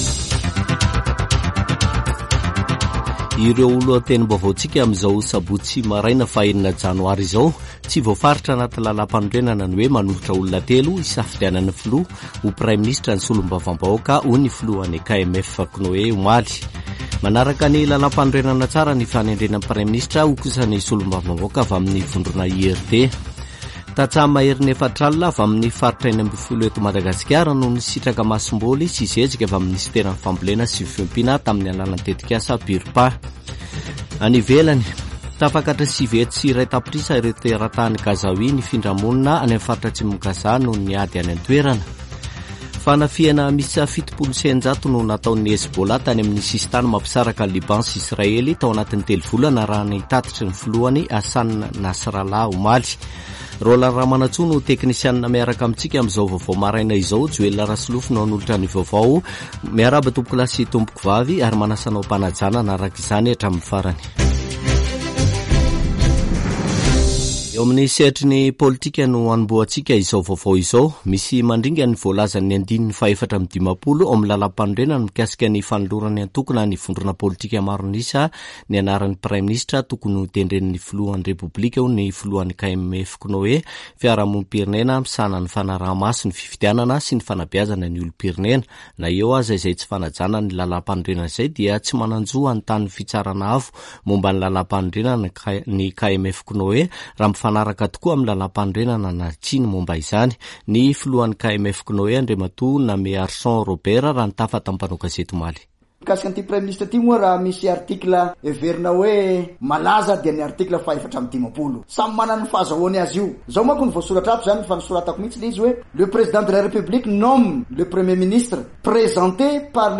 [Vaovao maraina] Sabotsy 6 janoary 2024